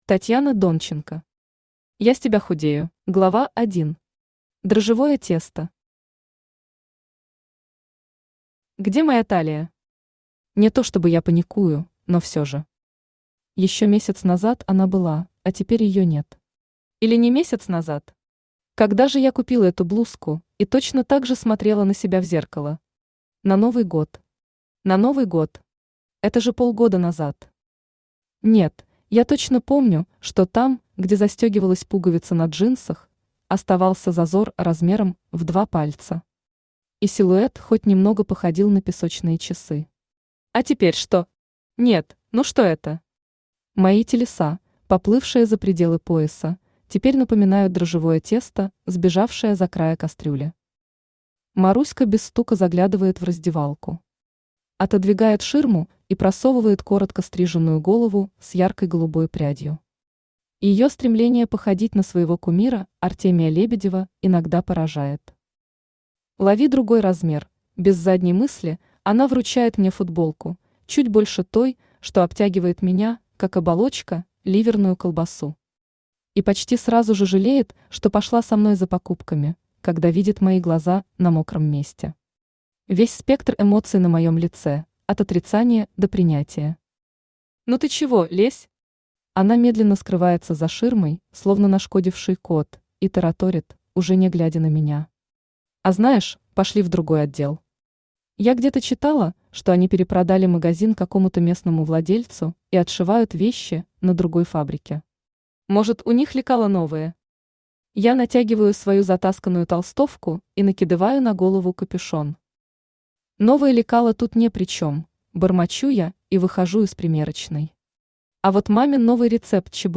Aудиокнига Я с тебя худею Автор Татьяна Донченко Читает аудиокнигу Авточтец ЛитРес.